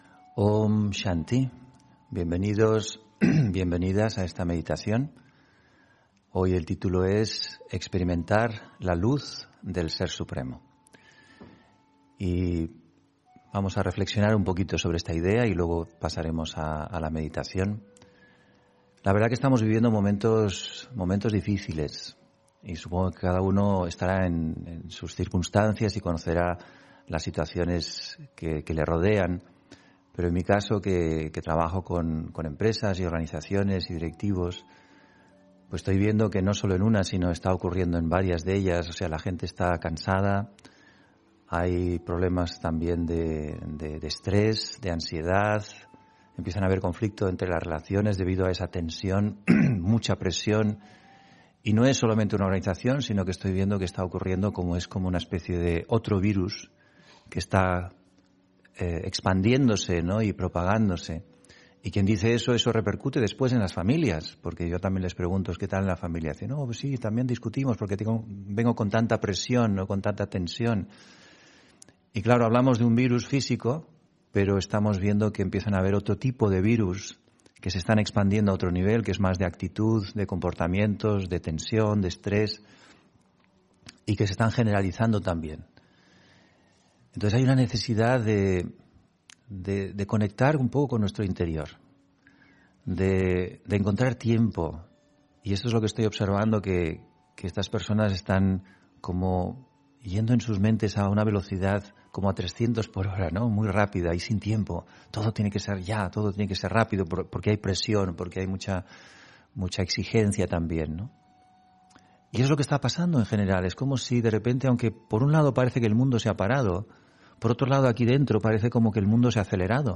Meditación Raja Yoga de la mañana: Beneficios de la Meditación Raja Yoga 3 (13 Marzo 20 ...